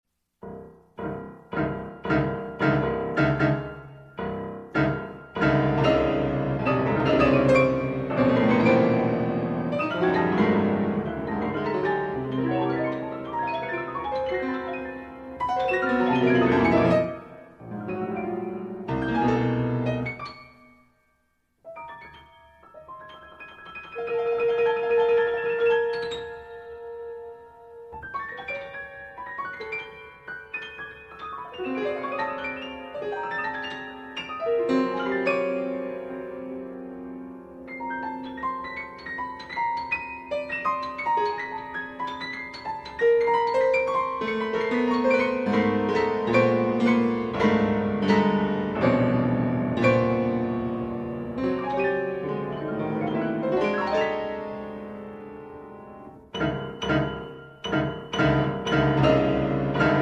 Disklavier